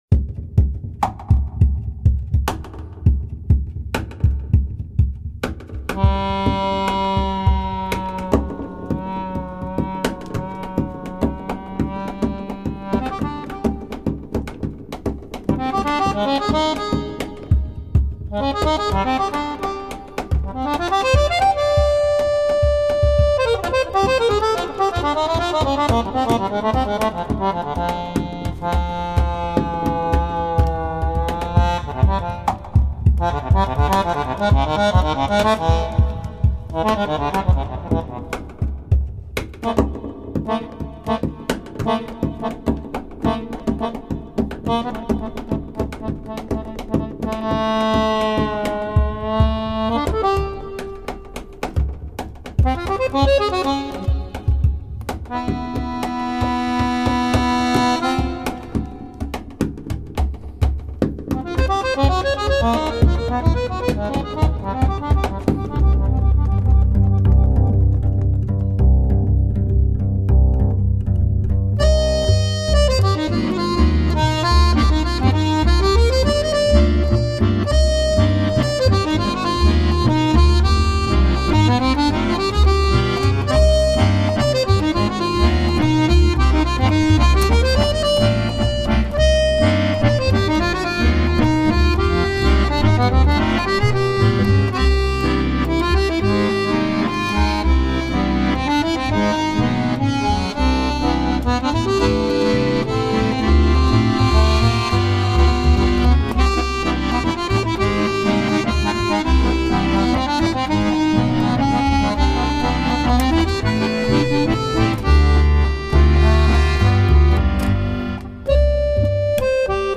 fisarmonica